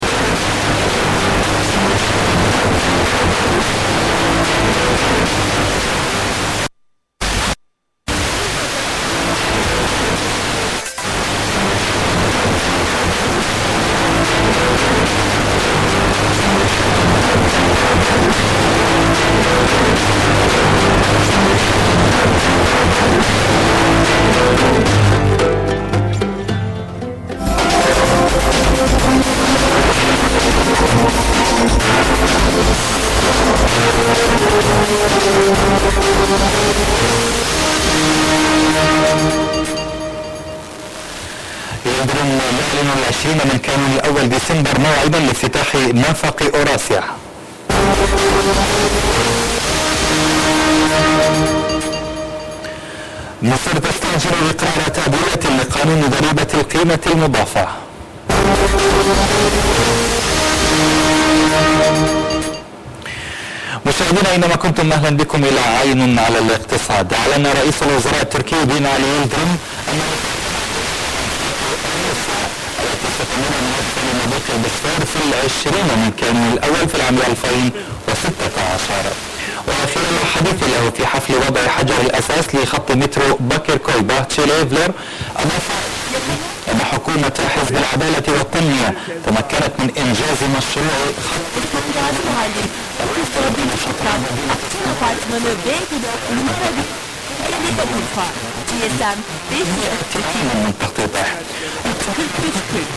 - itt egy felvétel...az az érzésem, hogy egy TV adás hangját sugározzák FM adóval...
Mondjuk igen mert a felvételemen is alámondásos szinkron ment.